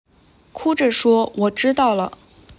speech generation